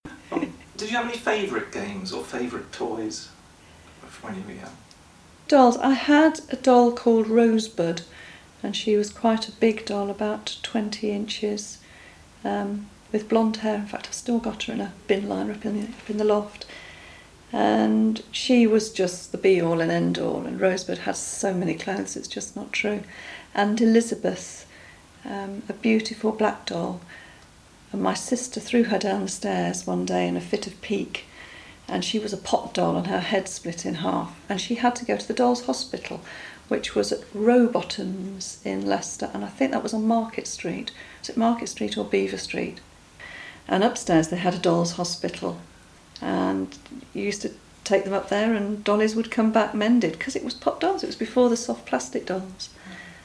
16 sound clips (six sound effects and 10 spoken descriptions of toys); PDF of 6 bingo cards to print (1 has pictures to match purely the sound effects, 1 has pictures to match with all spoken descriptions, 4 are a mixture of sound effects and spoken words).